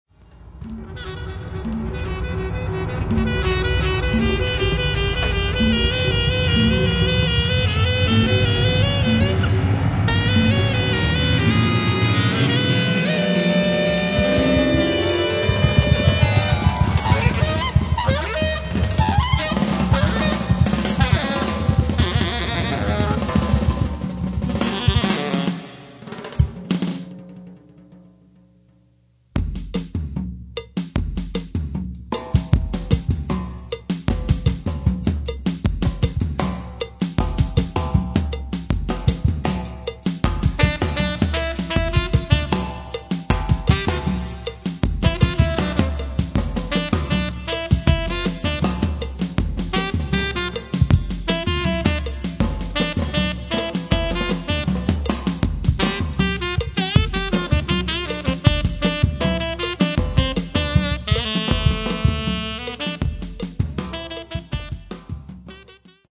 and bass, guitars, keyboard, sax and trumpet.